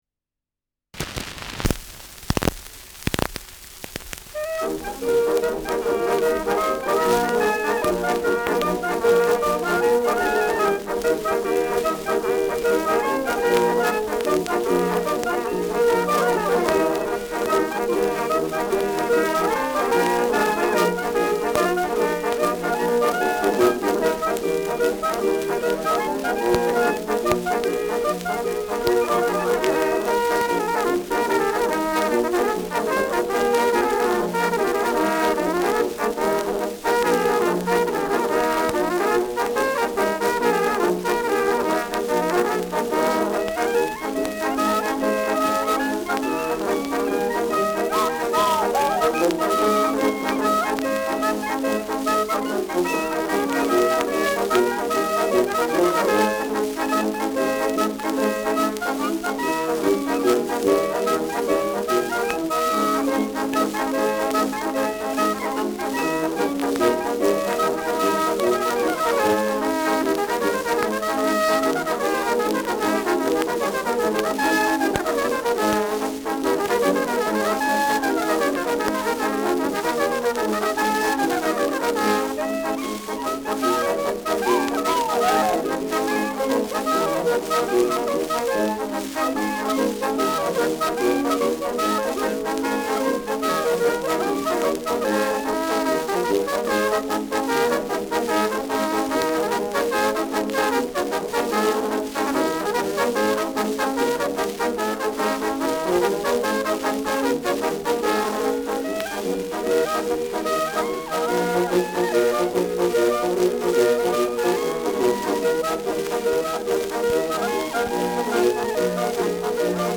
Schellackplatte
Stärkeres Grundrauschen : Durchgehend leichtes bis stärkeres Knacken : Pfeifton im Hintergrund